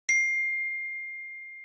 new_message_notification.mp3